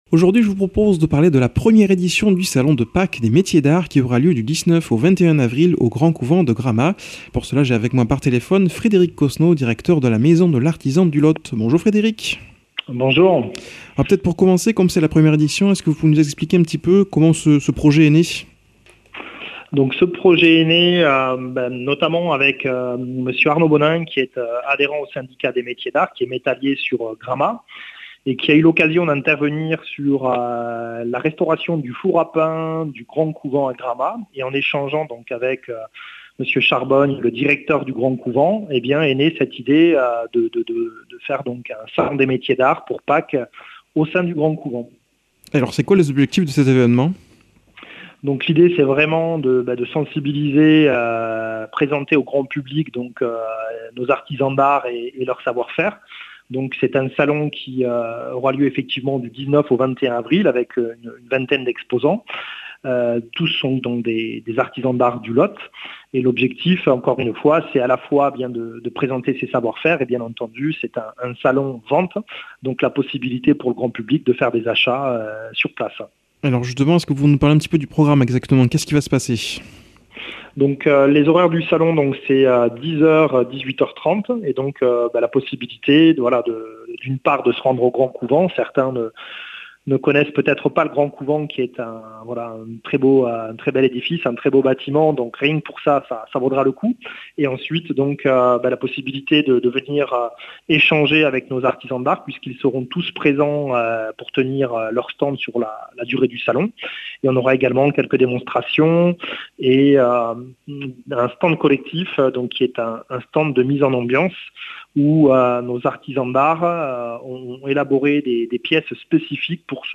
Présentateur